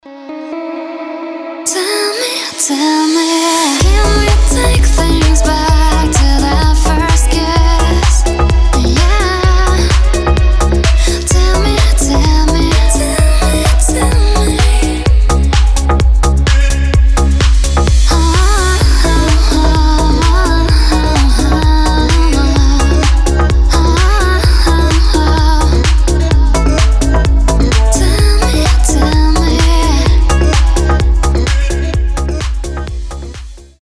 • Качество: 320, Stereo
deep house
приятный женский голос
Приятное женское исполнение